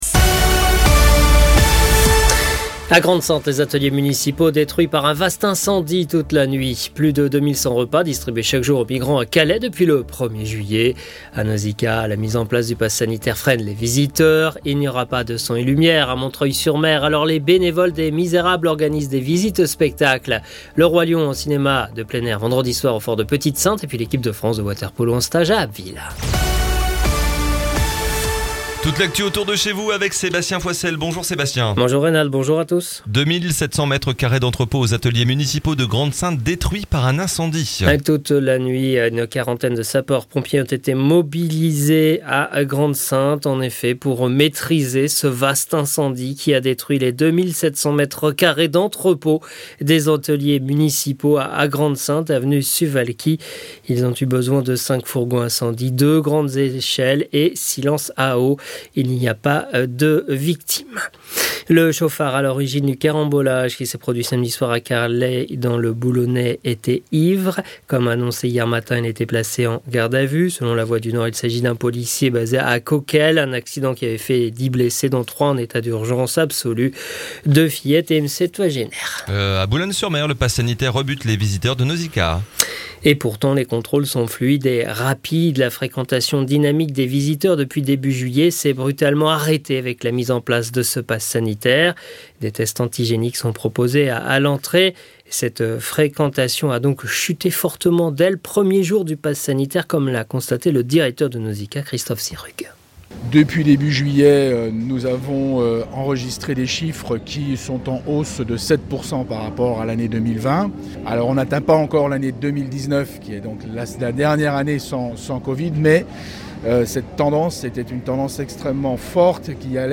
Le journal du mercredi 28 juillet